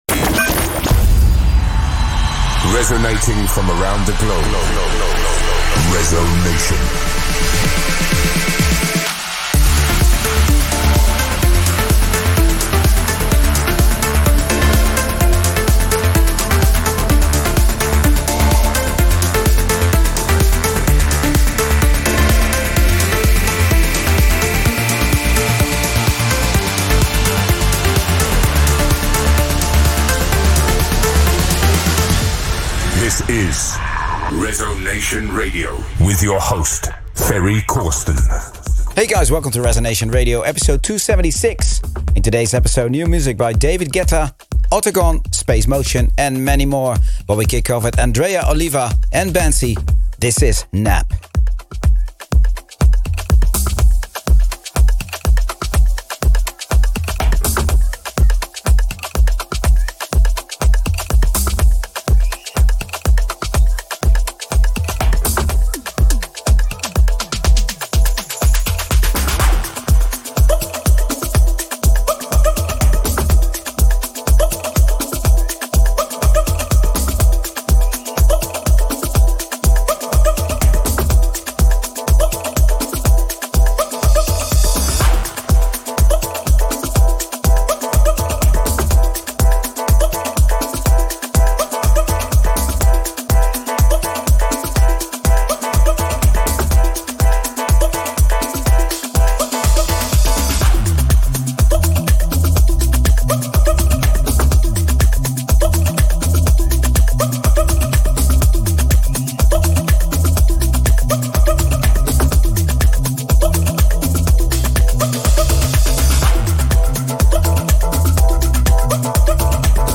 cutting-edge electronic music